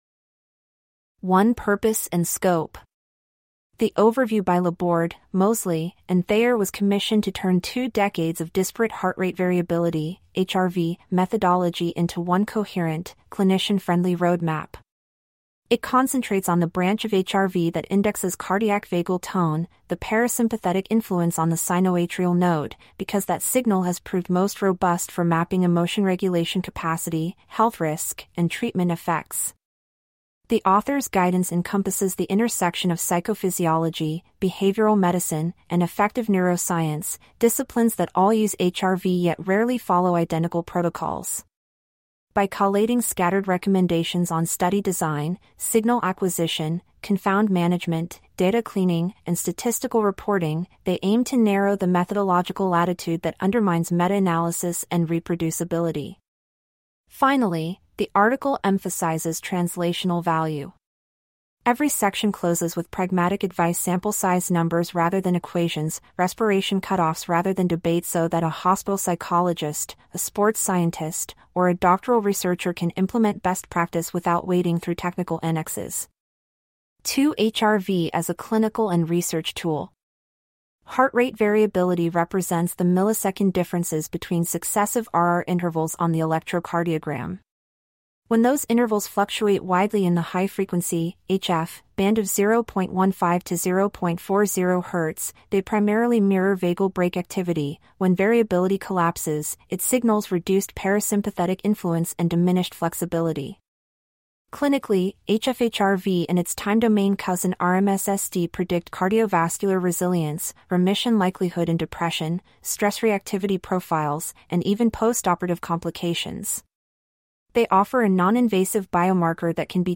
CLICK TO HEAR A LIVELY GOOGLE ILLUMINATE DISCUSSION OVER THIS POST The authors' guidance encompasses the intersection of psychophysiology, behavioral medicine, and affective neuroscience, disciplines that all use HRV yet rarely follow identical protocols.